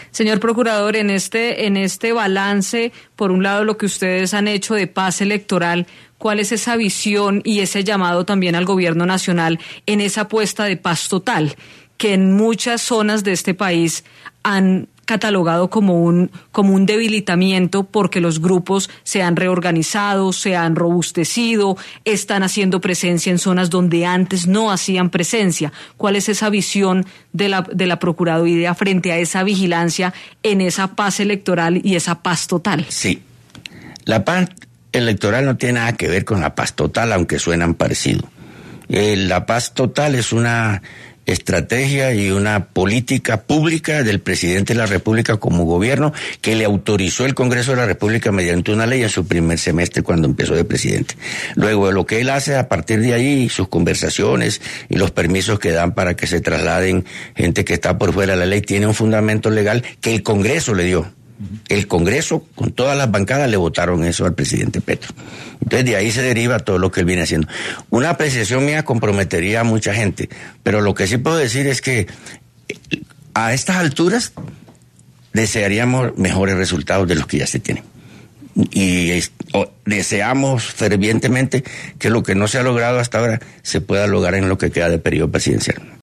Este martes, 27 de enero, en los micrófonos de 6AM W, con Julio Sánchez Cristo, habló el procurador general de la Nación, Gregorio Eljach, sobre diferentes temas coyunturales del país y, dentro de estos, se refirió a uno puntual: la paz total promovida por el Gobierno Nacional.